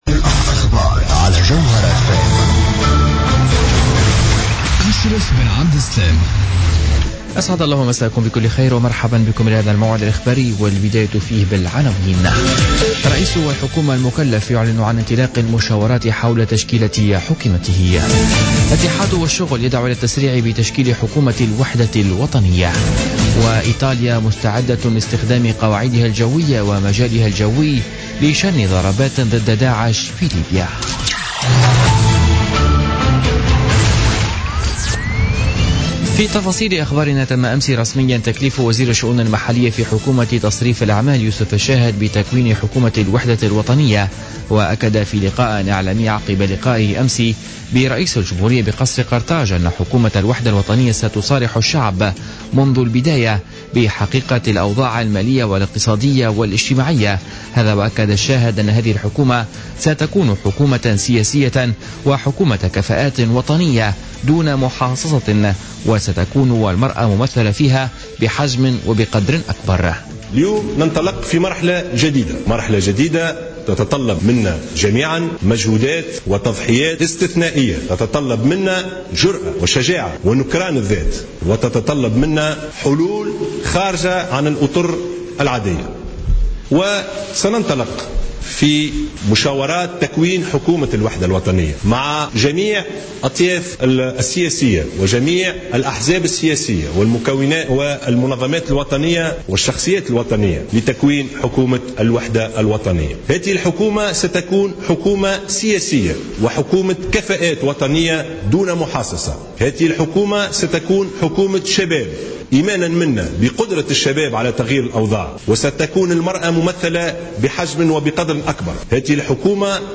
نشرة أخبار منتصف الليل ليوم الخميس 4 أوت 2016